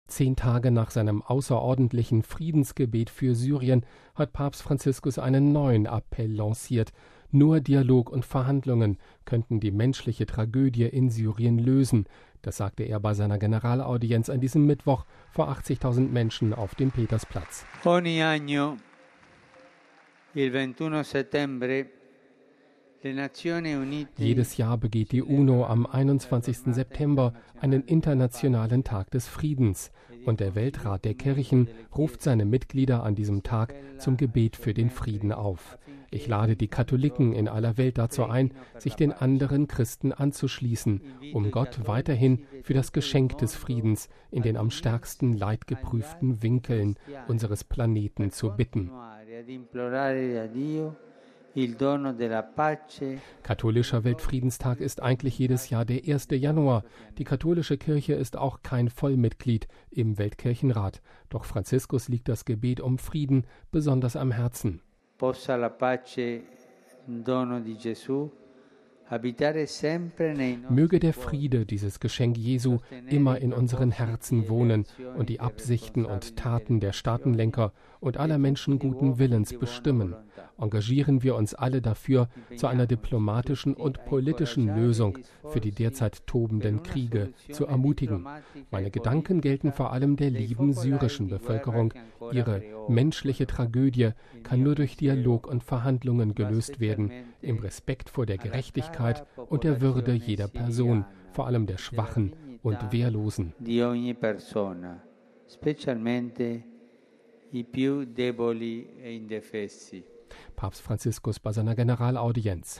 MP3 Zehn Tage nach seinem außerordentlichen Friedensgebet für Syrien hat Papst Franziskus einen neuen Appell lanciert. „Nur Dialog und Verhandlungen“ könnten die „menschliche Tragödie“ in Syrien lösen, sagte er bei seiner Generalaudienz an diesem Mittwoch vor 80.000 Menschen auf dem Petersplatz.